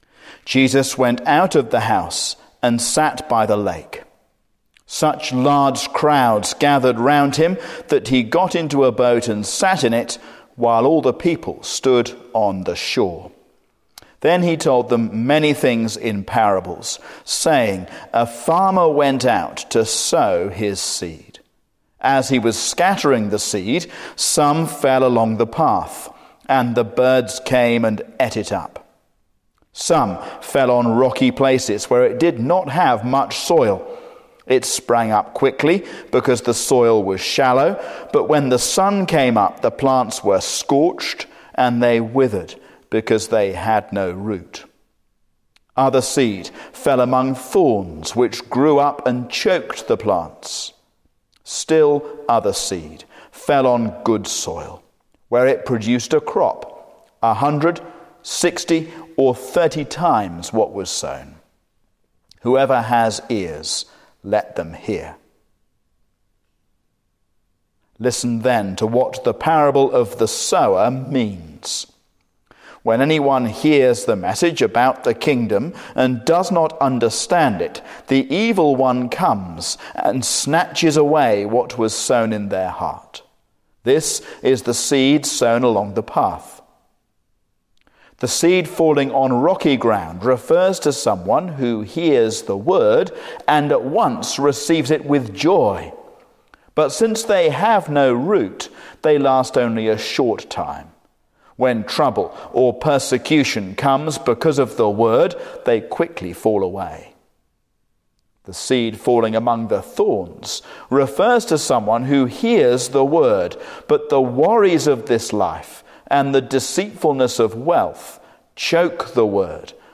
A sermon on Matthew 13:1-23